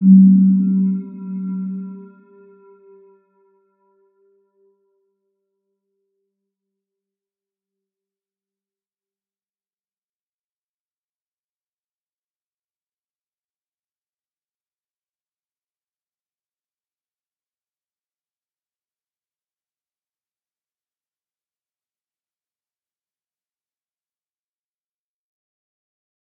Round-Bell-G3-p.wav